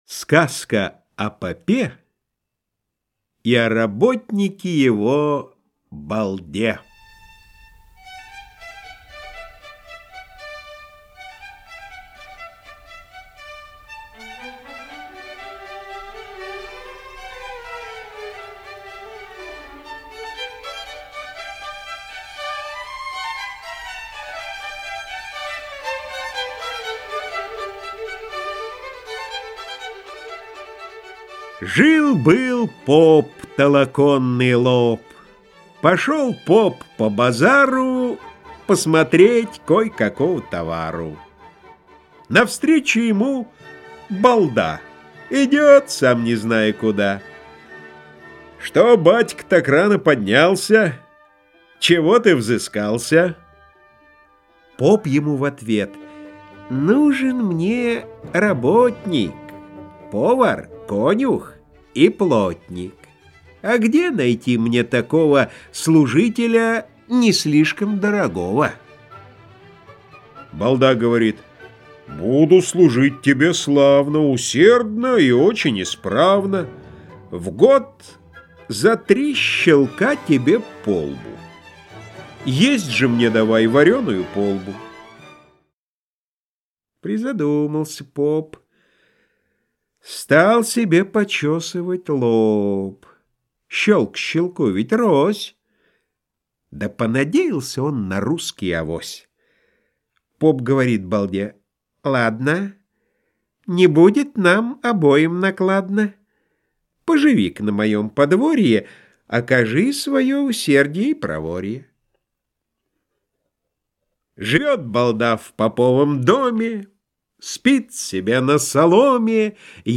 Аудиокнига Сказки. У Лукоморья | Библиотека аудиокниг